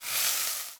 Sear.wav